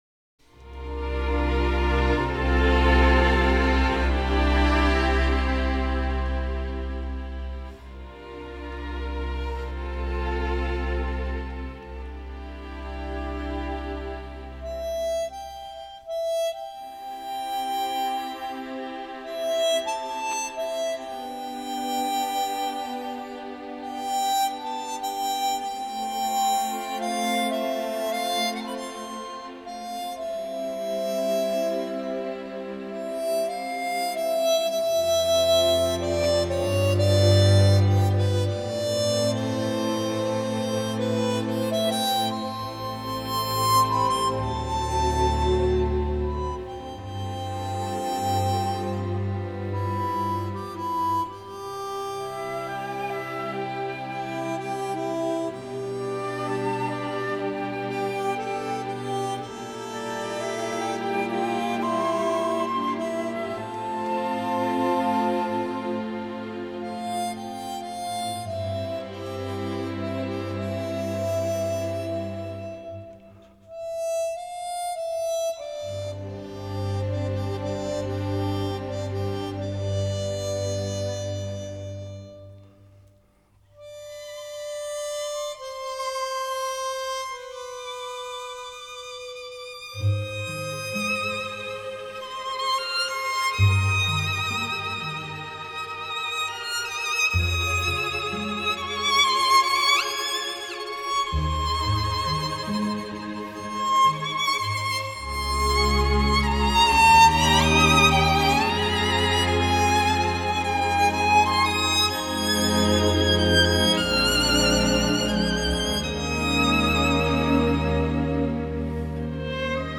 最抒情的口琴演奏
加上管弦乐团伴奏，呈现出这些音乐最优雅而富气质的一面